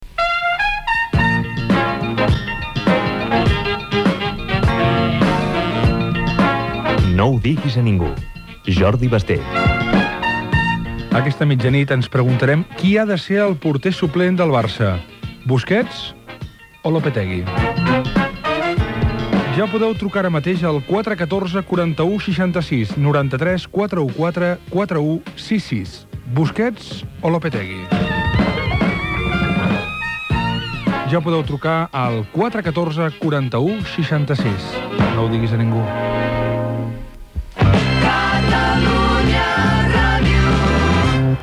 Promoció del programa d'aquell dia, amb el tema i el telèfon de participació. Indicatiu de l'emissora
FM